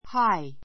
hi gh 小 A1 hái ハ イ （ ⦣ gh は発音しない） 形容詞 比較級 higher háiə r ハ イア 最上級 highest háiist ハ イエ スト ❶ （高さが） 高い , 高さが～で 反対語 low （低い） 関連語 「（木や建物などが）高い」は tall . a high mountain ♔基本 a high mountain 高い山 ⦣ high＋名詞.